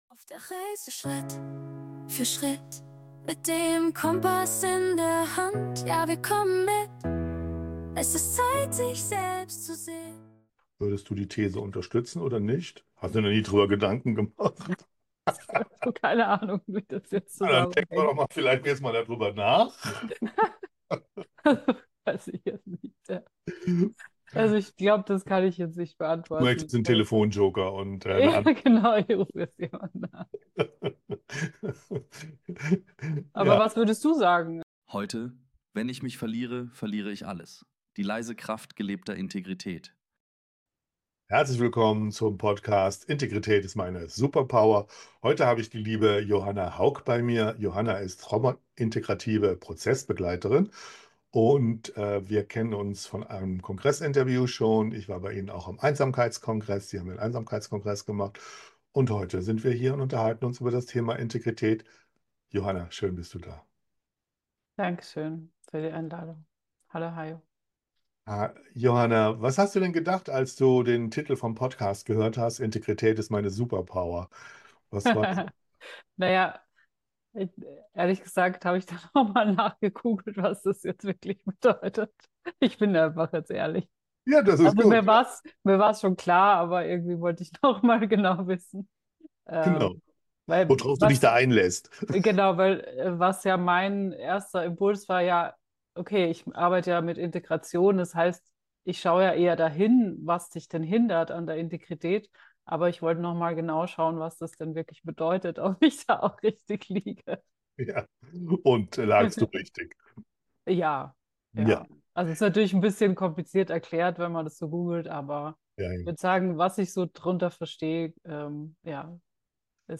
Dann ist dieses Gespräch ein Weckruf für deine Seele.